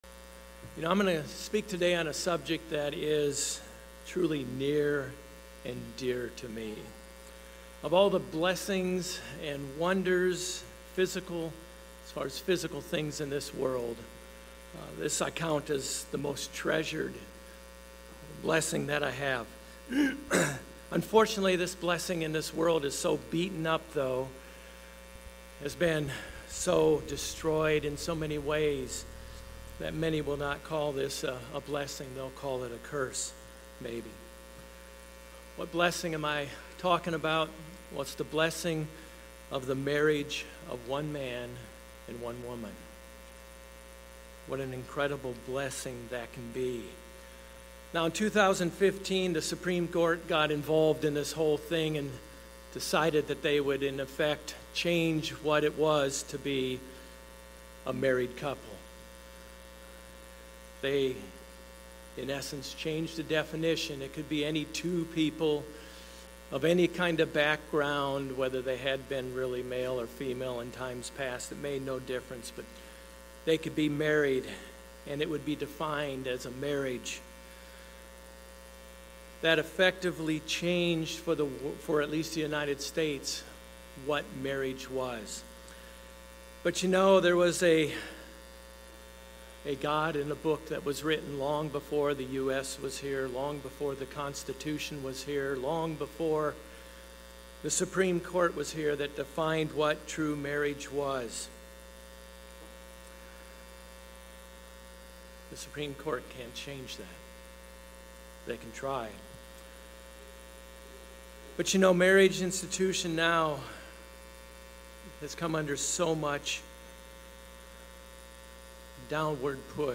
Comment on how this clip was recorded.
Given in Orlando, FL Jacksonville, FL